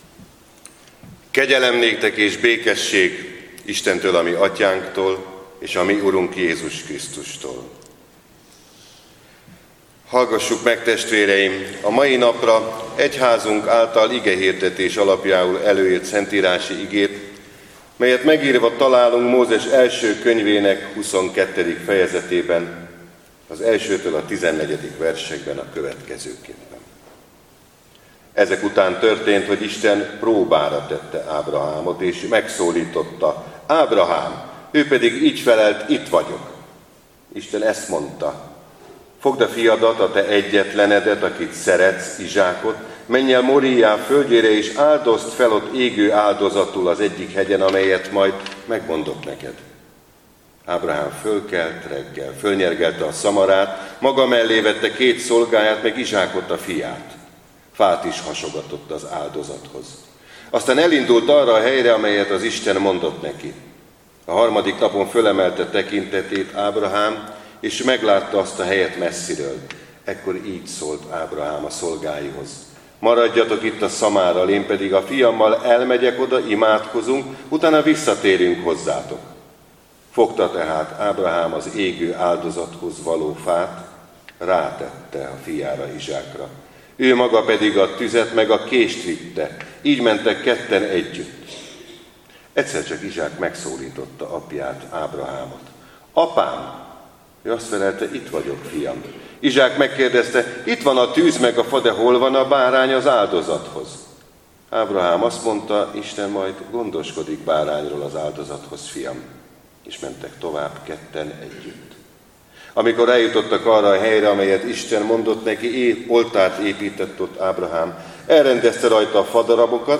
Böjt 5. vasárnapja - Ítélj meg, Istenem, és légy pártfogóm a hűtlen néppel szemben, ment meg az alattomos és álnok emberektől!